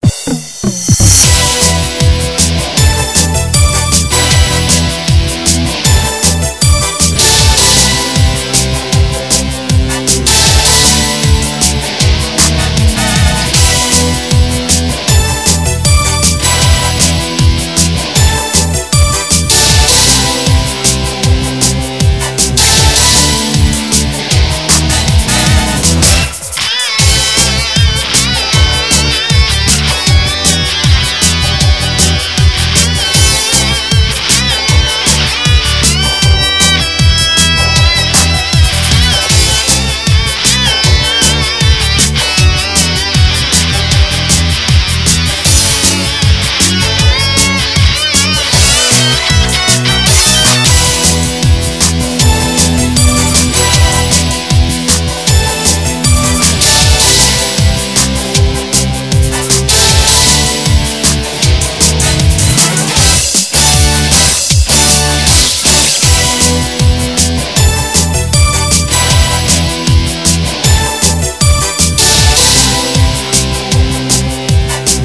HPZa2.ogg 2008 or earlier The drum fill at the beginning is not part of the original track and was edited in.